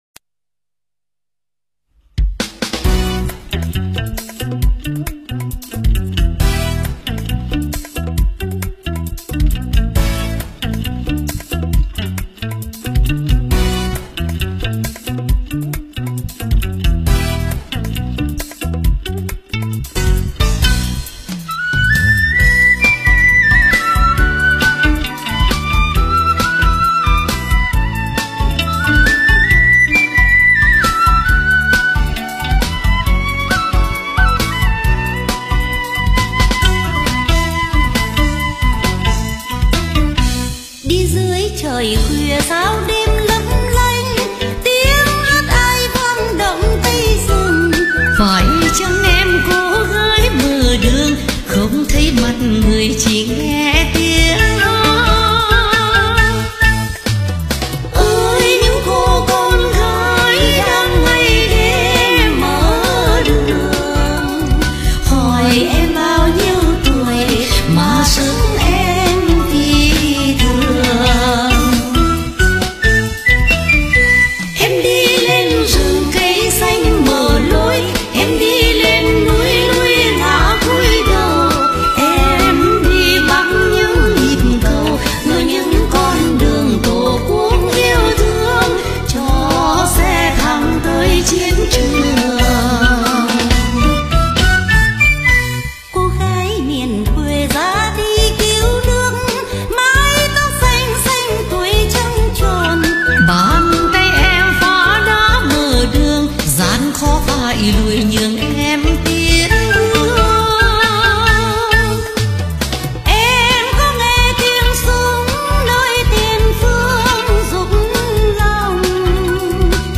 Nhạc Xưa
Nhạc Bolero Trữ Tình